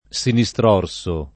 sinistrorso [ S ini S tr 0 r S o ] agg.